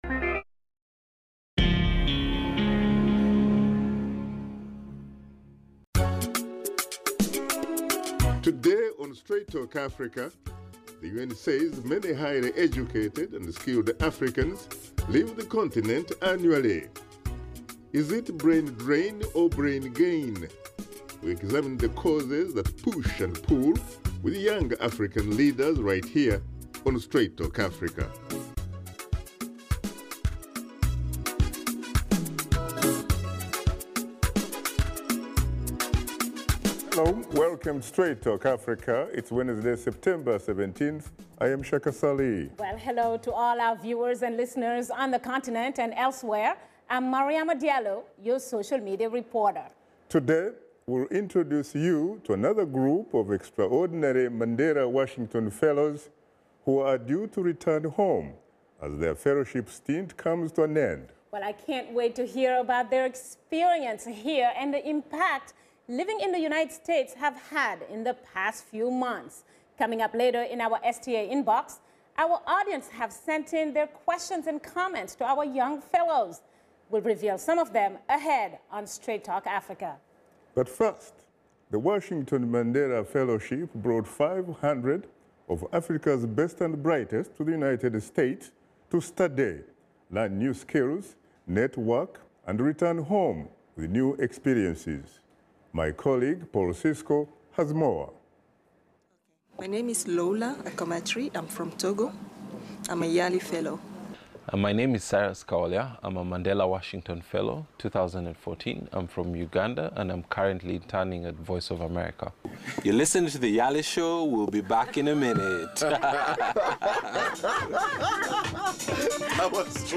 discuss the issue of African students and young professionals who study and live abroad and their reasons for not returning to Africa Washington Studio Guests